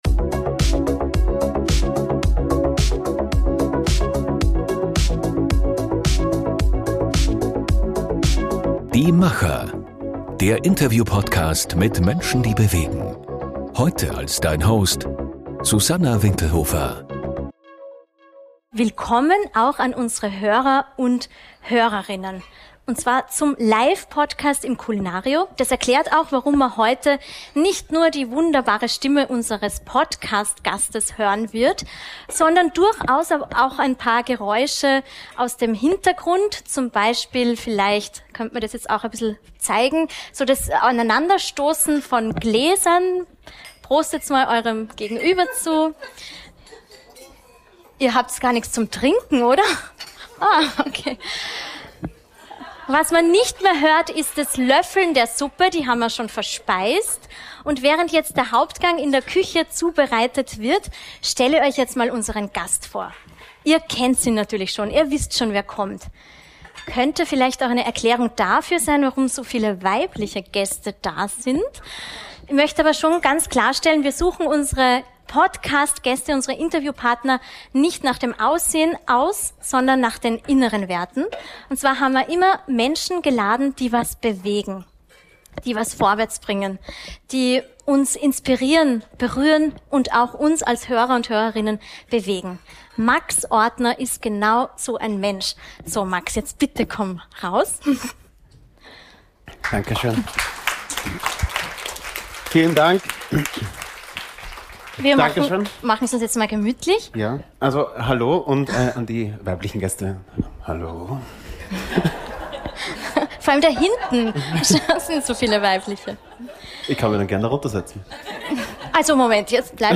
Ein Gespräch über Durchhaltevermögen, Selbstfindung und den Mut, auch nach dem härtesten Schlag nie aufzugeben.